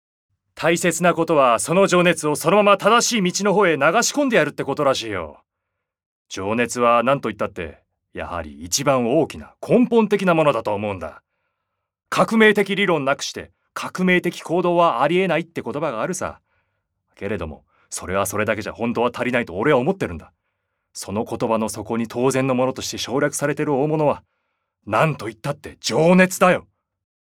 セリフB
ボイスサンプル